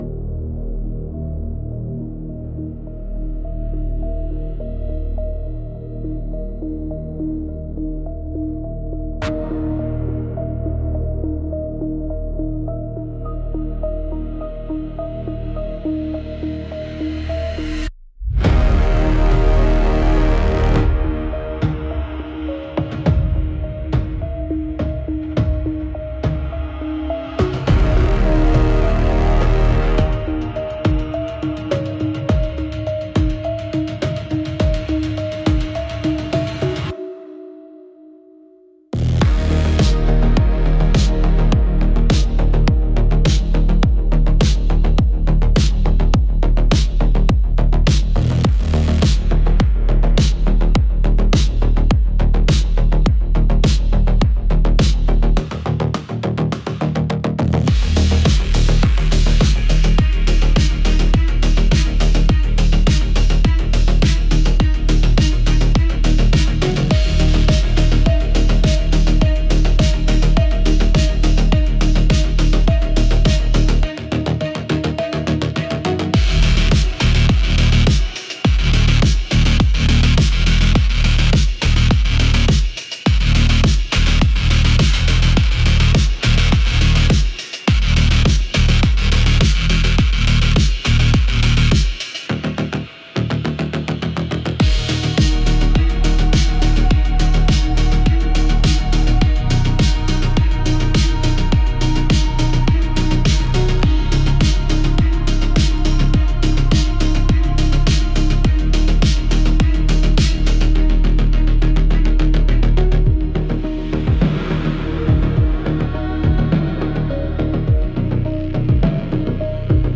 Instrumental - Touchstones To Tyranny 3. 28 mins